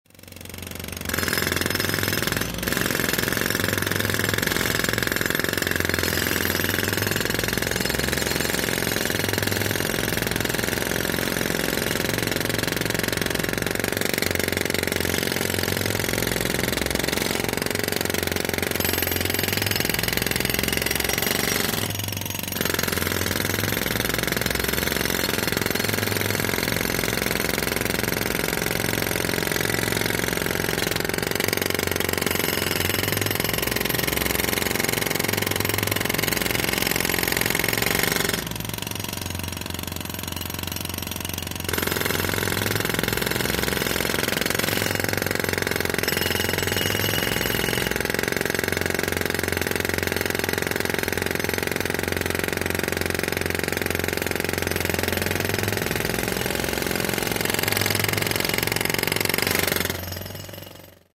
Звуки отбойного молотка
Демонтаж дороги молотком